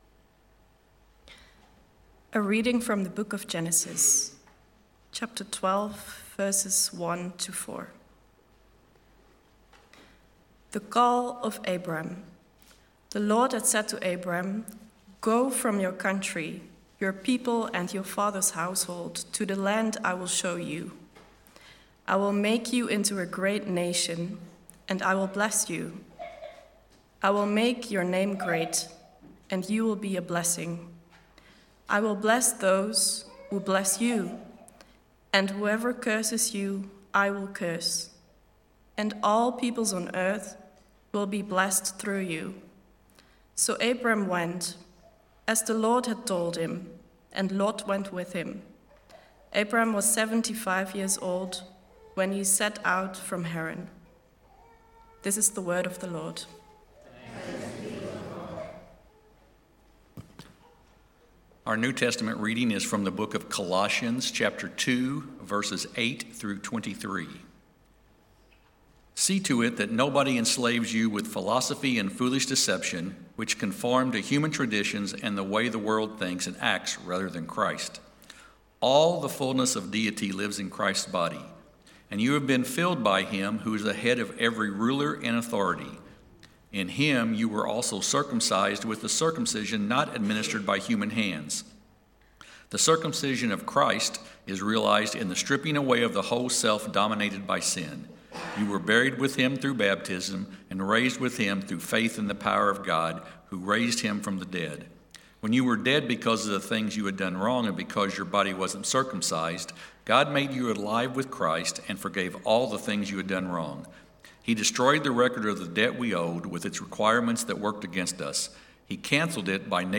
TCE_Sermon-March-3-2026.mp3